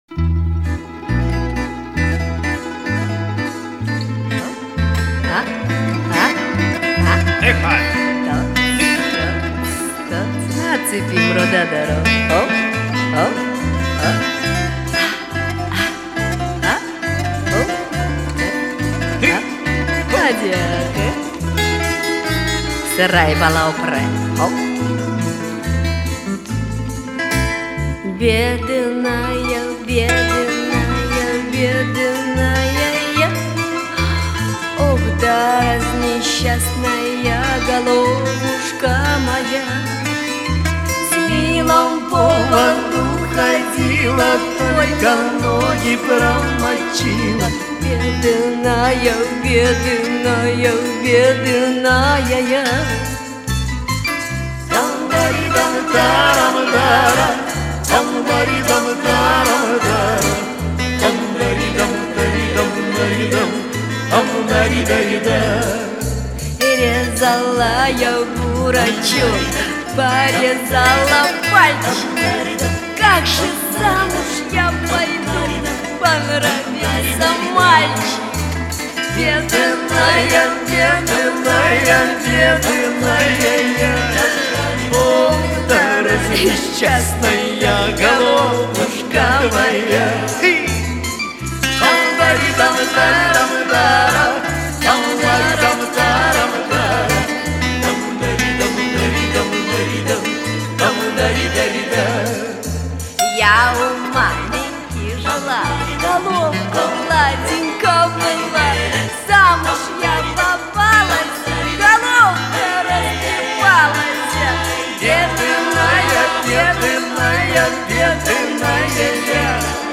классно поет!